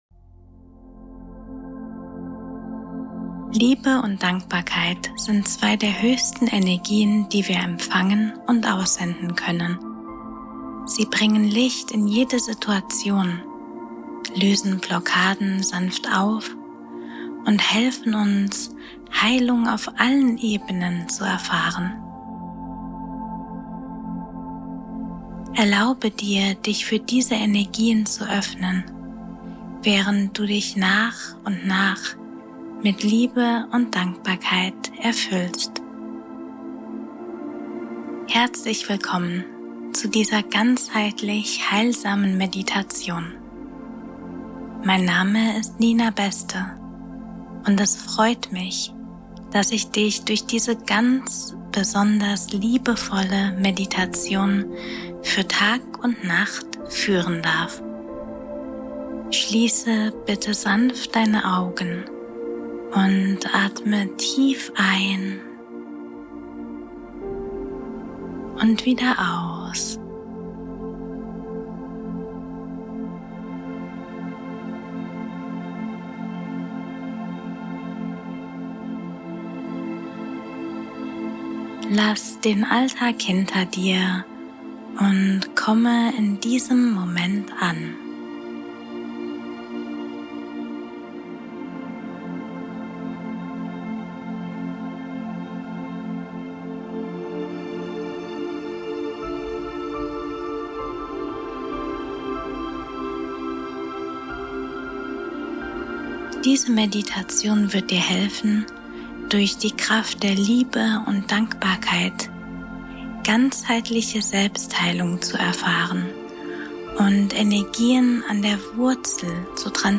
Quantum-Kinderheilung - 1.5 Hz Binaural repariert dein inneres Kind